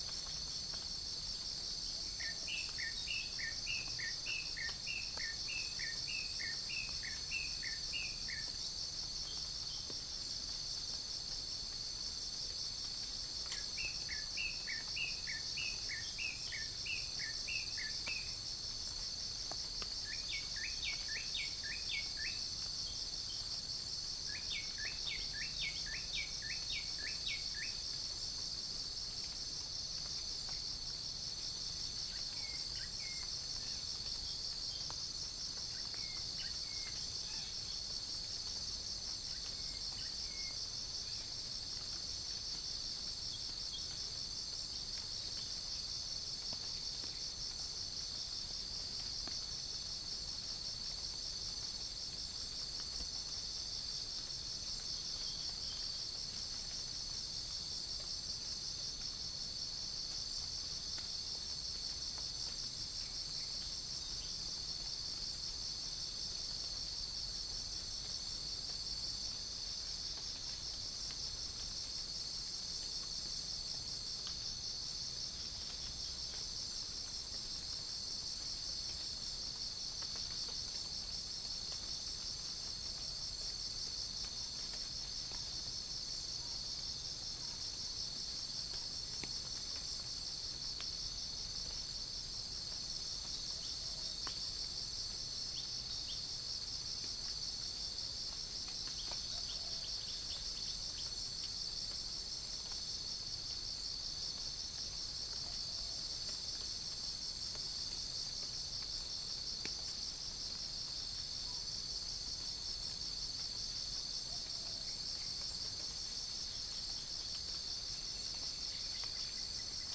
Chalcophaps indica
Spilopelia chinensis
Pycnonotus goiavier
Orthotomus sericeus
unknown bird
Prinia familiaris
Orthotomus ruficeps